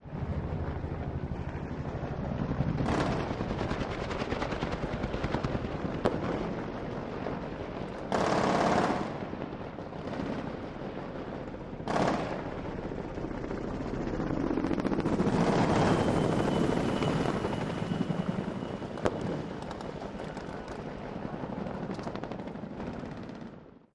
Battle simulation with rapid fire weapons and jet and helicopter flybys. Weapons, Gunfire Bombs, War Battle, Military